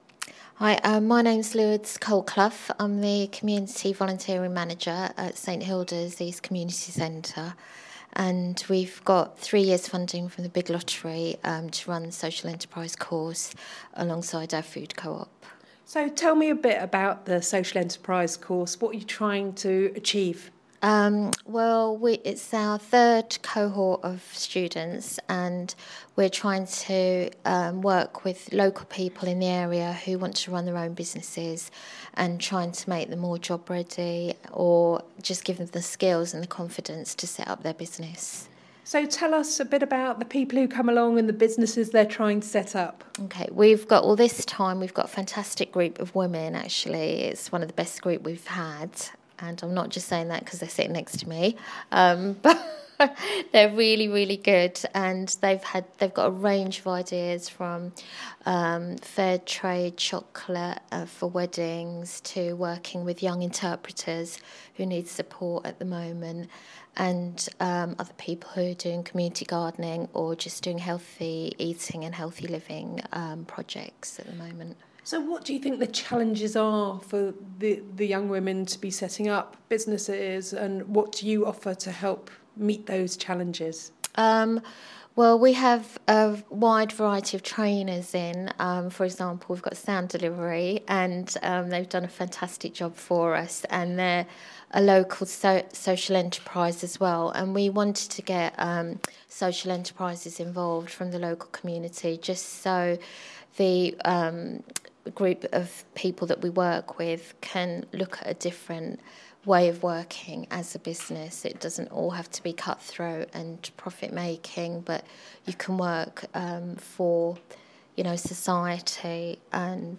In this interview she talks about the St Hilda's Food Co-op project funded by the Big Lottery Fund which has received funding for 3 years to run accredited social enterprise training for food related projects. The funding has made a huge difference to the organisation.